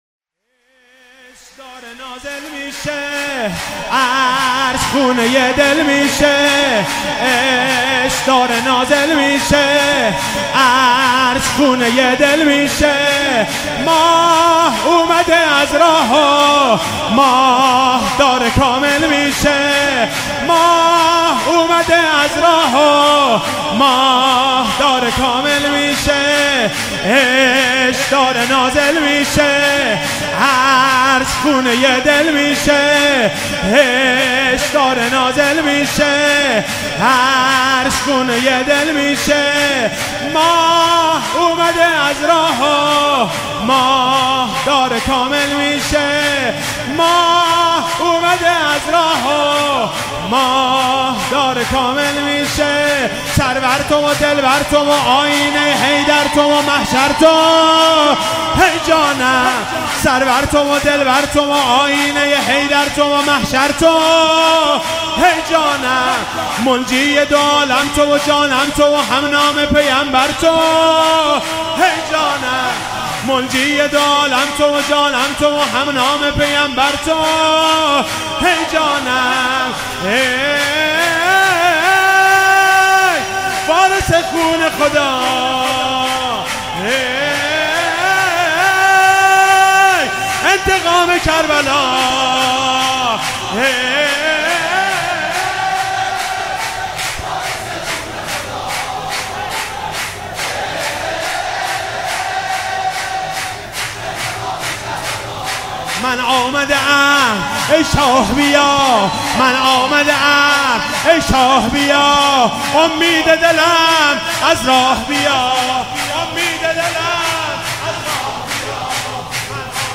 ولادت امام زمان (عج)98 - سرود - عشق داره نازل میشه
ولادت امام زمان علیه السلام